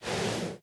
horse_breathe2.ogg